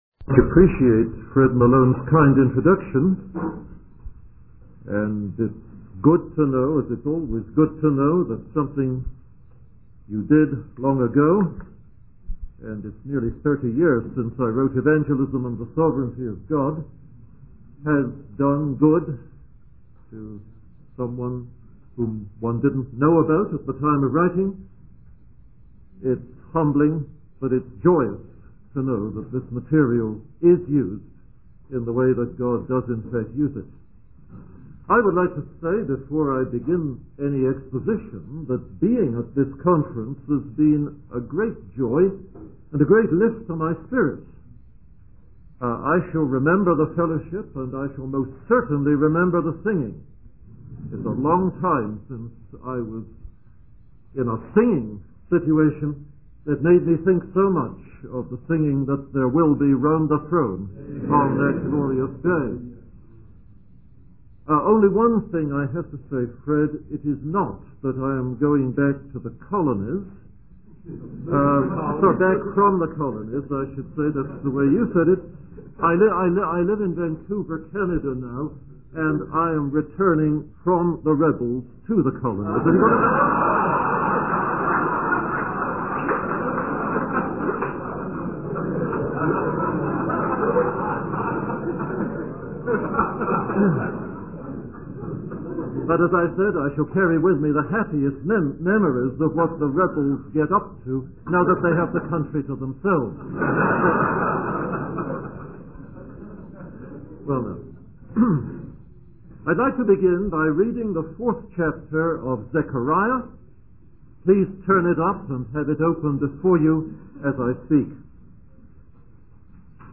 In this sermon, the speaker focuses on Zechariah chapter four and verse one, which describes a vision of a solid gold lampstand with seven lights and two olive trees. The speaker emphasizes that this vision represents the word of the Lord to the rubble, emphasizing that God's work is not accomplished by human might or power, but by His spirit.
Additionally, the speaker clarifies that the conference is not a political movement, but rather a gathering for the personal edification of pastors, elders, deacons, and members of Baptist churches.